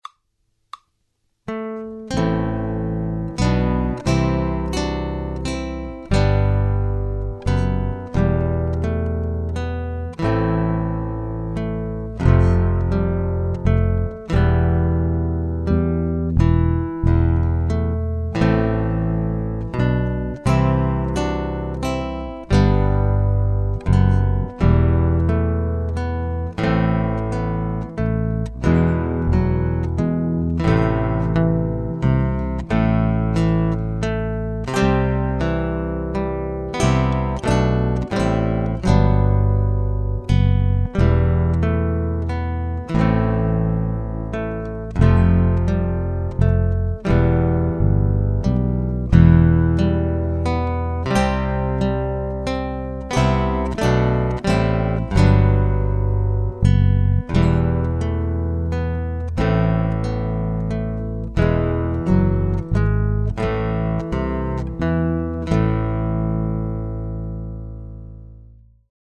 guitar ensembles in a variety of styles